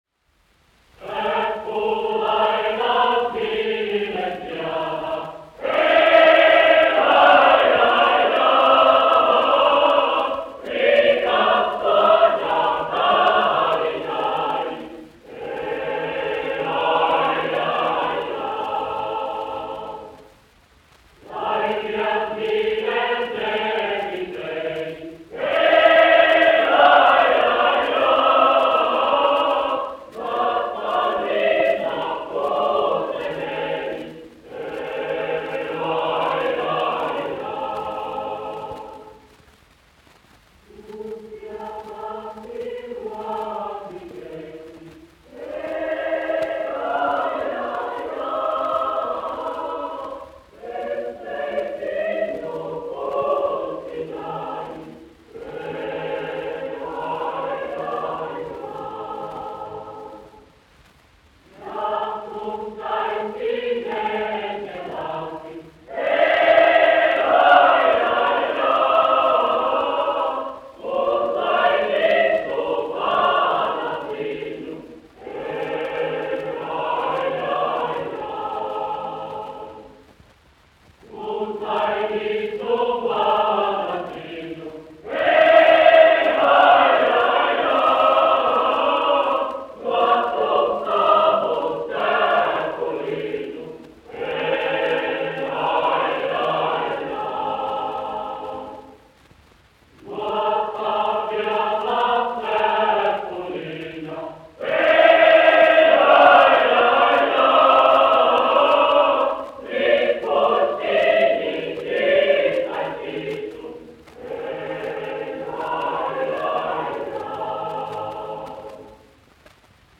Latvijas Nacionālā opera. Koris, izpildītājs
1 skpl. : analogs, 78 apgr/min, mono ; 25 cm
Latviešu tautasdziesmas
Kori (jauktie)
Skaņuplate